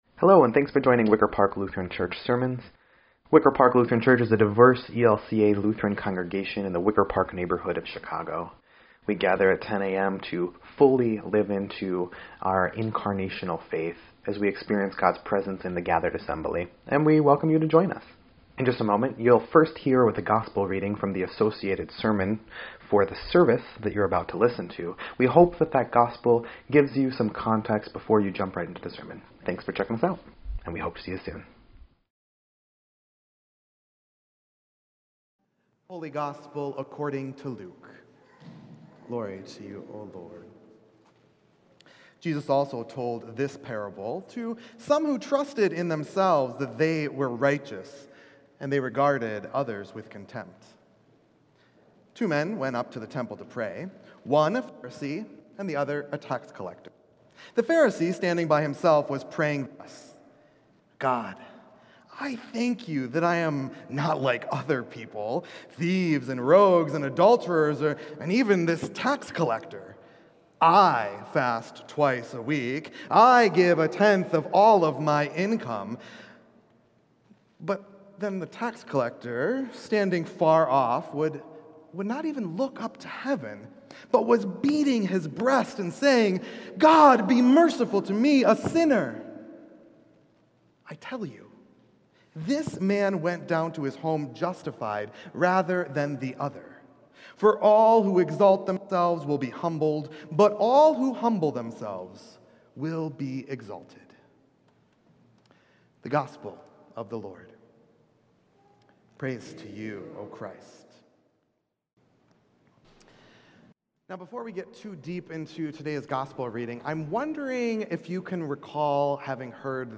Twentieth Sunday after Pentecost